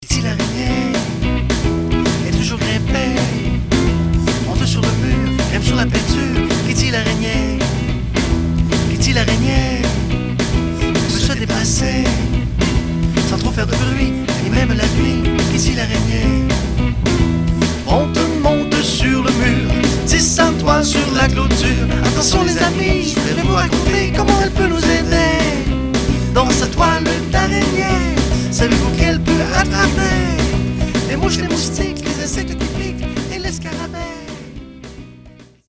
en mimes et en chansons
tout en alternant le mime, la musique et le théâtre.